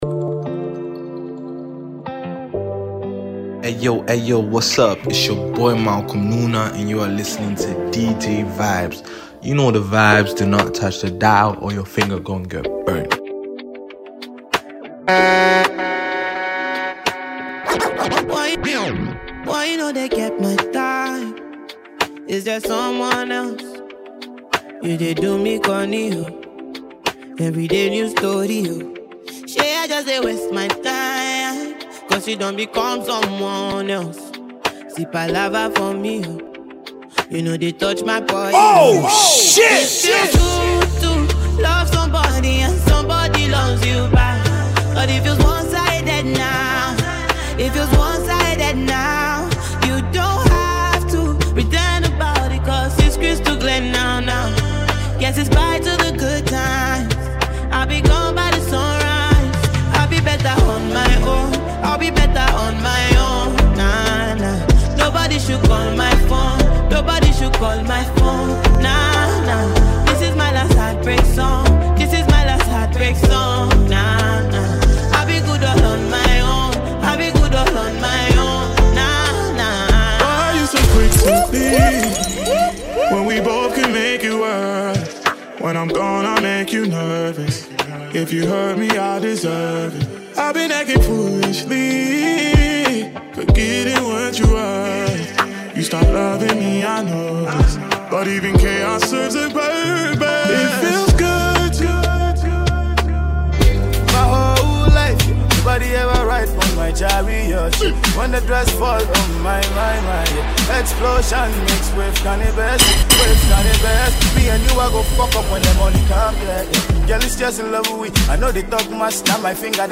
This is a banger all day.
DJ Mixtape